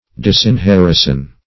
Disinherison \Dis`in*her"i*son\, n. [See Disinherit, v. t.,